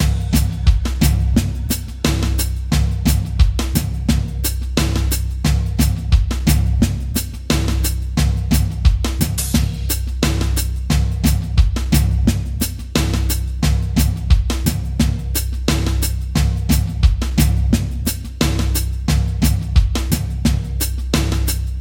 描述：petit loop glitchygroovytravailléavecconscience et finesse
Tag: 循环 电子 火腿 多汁 葡萄柚 YOLO Groovy的